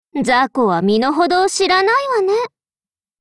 Cv-60303_battlewarcry.mp3